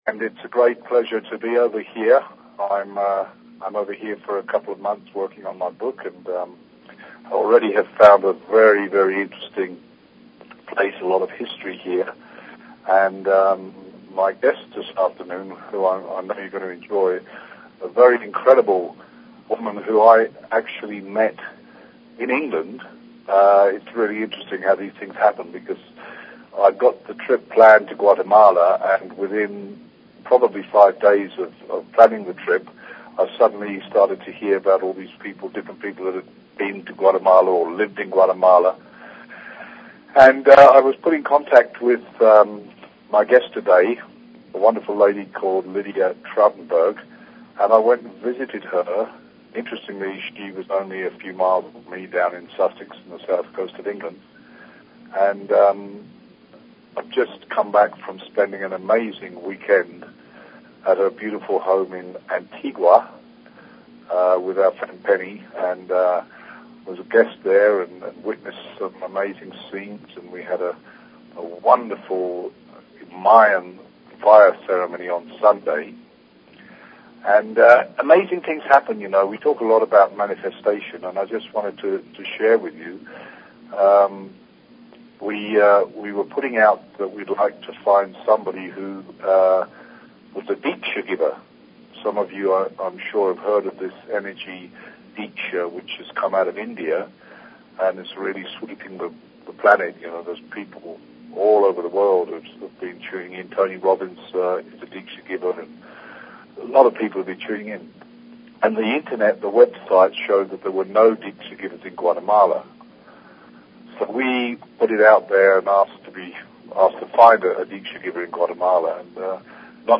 Talk Show Episode, Audio Podcast, Miracle_Hour and Courtesy of BBS Radio on , show guests , about , categorized as